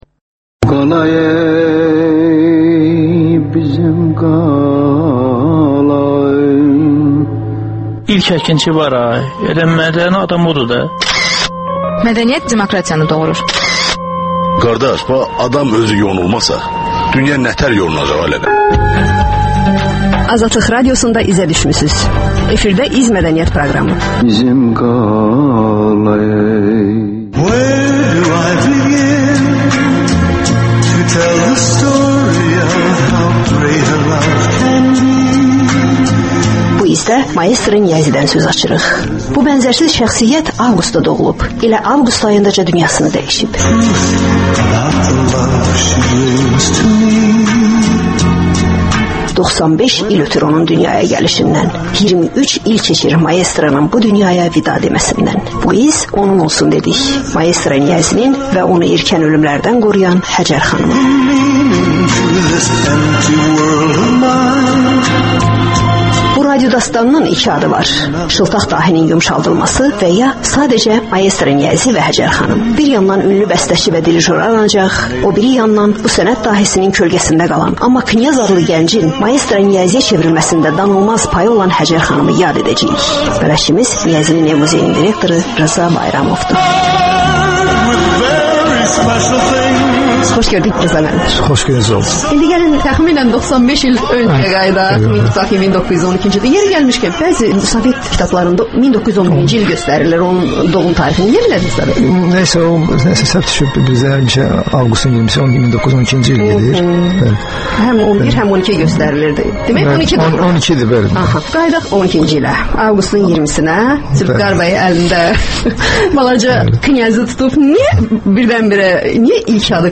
Ölkənin tanınmış simalarıyla söhbət